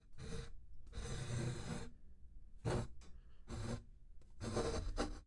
吱吱声；吱吱声 " 手提箱把手吱吱声 05
描述：有吱吱作响的把柄的老手提箱。 记录了一对Sennheiser MKH80，Daking Pre IV，Lucid 88192。
标签： 金属 木材
声道立体声